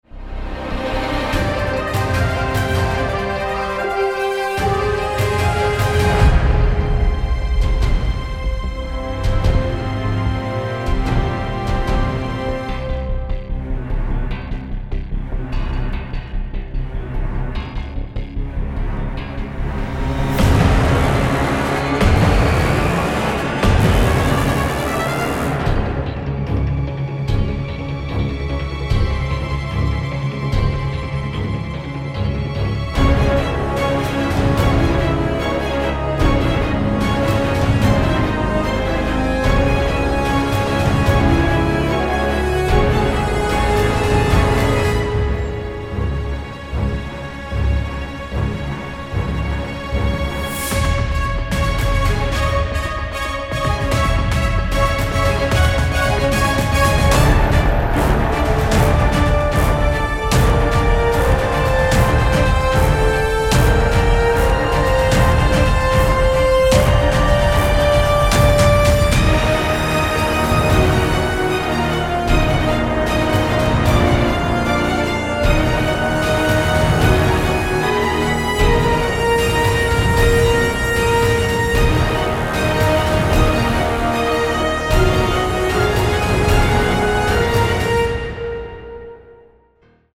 Dramatic, heartfelt and adventurous